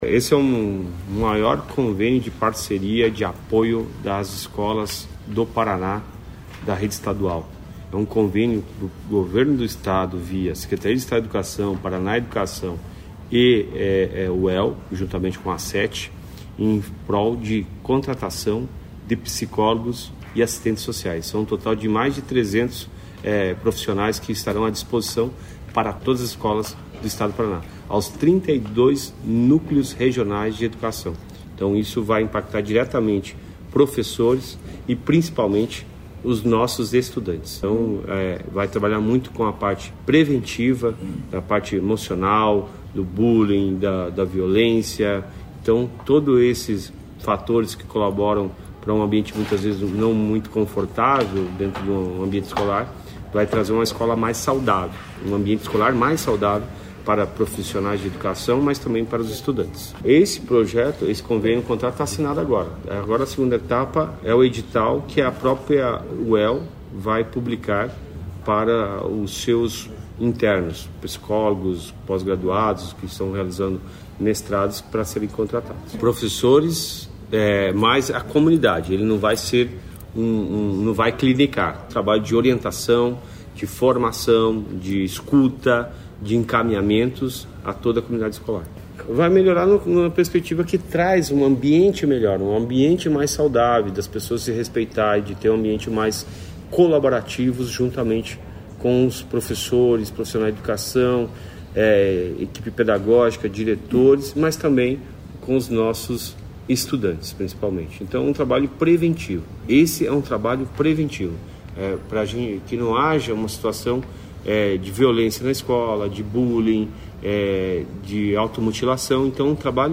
Sonora do secretário da Educação, Roni Miranda, sobre o novo projeto que visa capacitar e disponibilizar psicólogos e assistentes sociais para atendimento especializado nas escolas